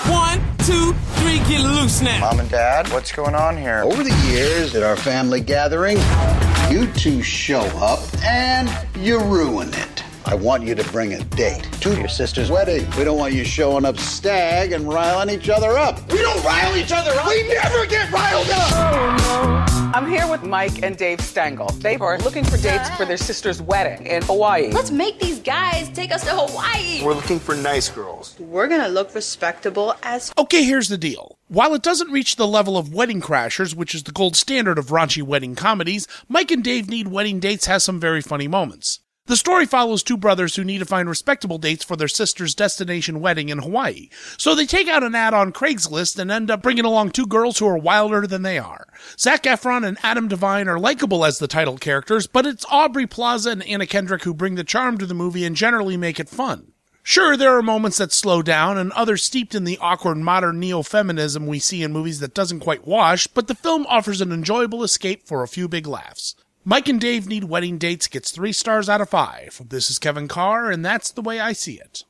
‘Mike and Dave Need Wedding Dates’ Radio Review